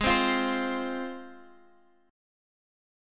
Listen to G#6 strummed